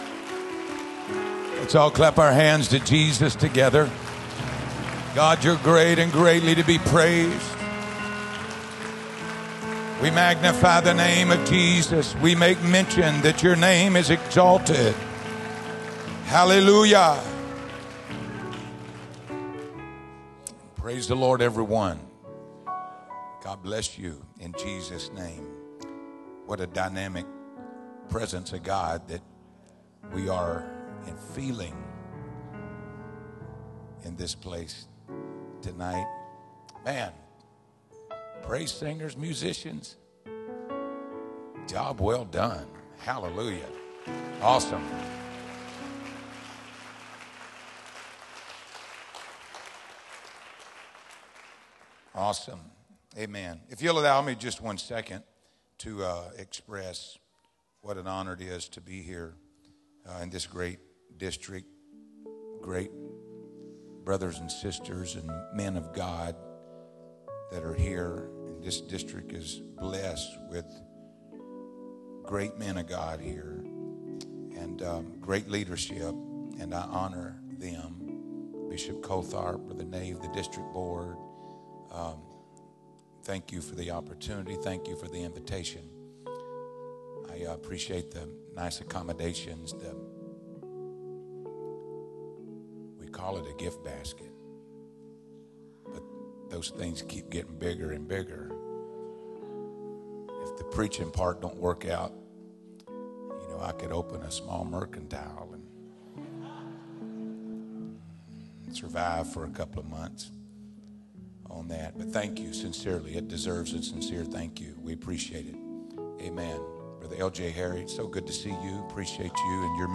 Sermon Archive | Illinois District
Camp Meeting 25 (Thursday PM)